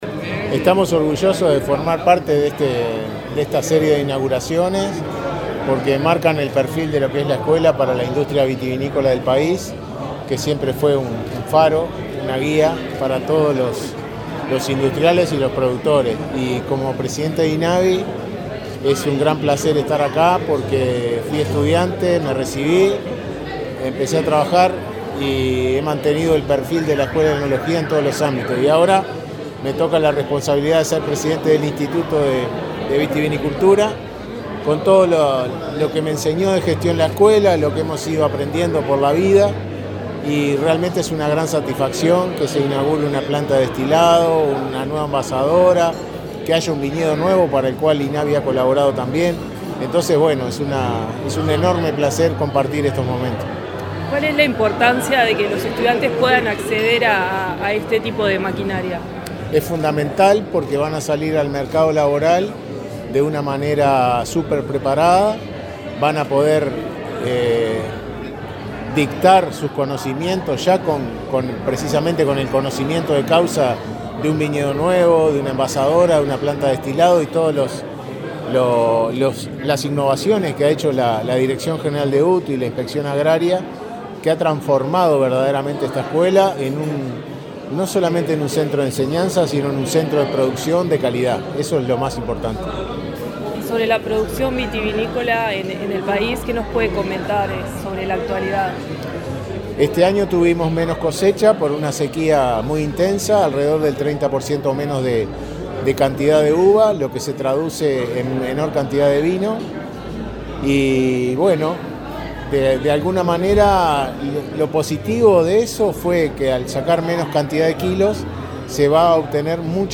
Entrevista al director del Inavi
Entrevista al director del Inavi 07/07/2023 Compartir Facebook X Copiar enlace WhatsApp LinkedIn El director del Instituto Nacional de Vitivinicultura (Inavi), Ricardo Cabrera, dialogó con Comunicación Presidencial durante la inauguración de una sala de destilado y una embotelladora en la Escuela Superior de Vitivinicultura de la UTU, en Las Piedras, departamento de Canelones.